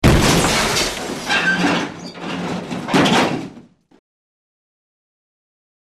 Мусор звуки скачать, слушать онлайн ✔в хорошем качестве